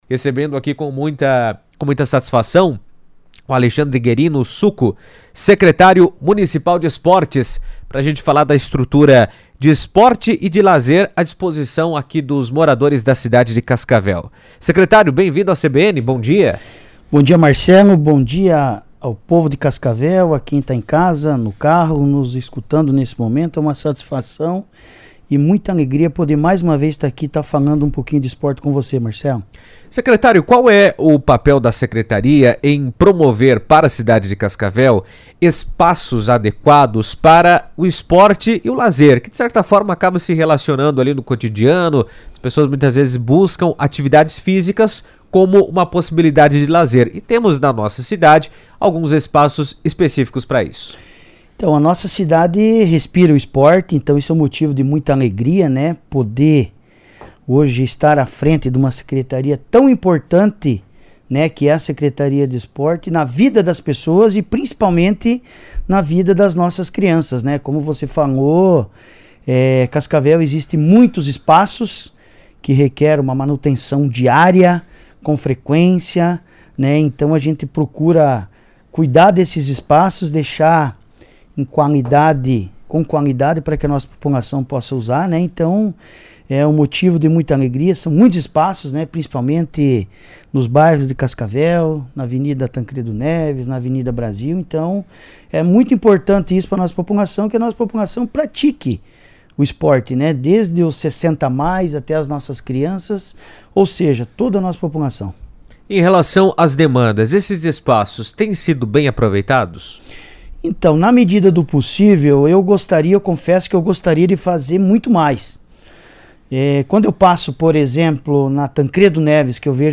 Cascavel conta com diversos espaços de esporte e lazer, como quadras poliesportivas, piscinas e áreas de convivência, oferecendo opções para todas as idades e incentivando a prática de atividades físicas. Em entrevista à CBN, o secretário de Esportes, Alexandre Guerino, o Suco, destacou a importância desses espaços para a saúde da população, além de reforçar o papel do município em promover inclusão, bem-estar e qualidade de vida por meio do esporte e do lazer.